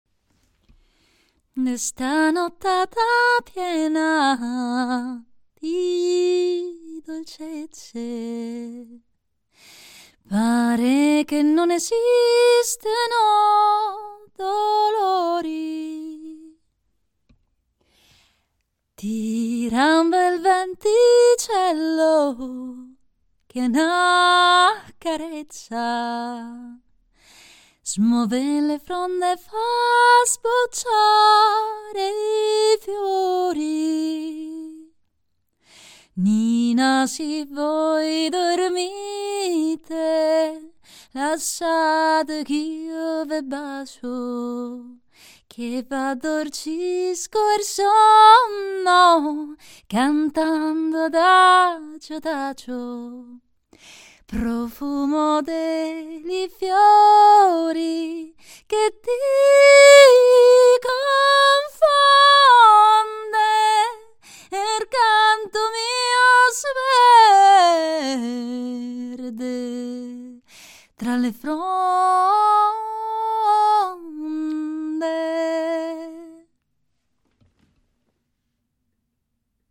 Frammento cantato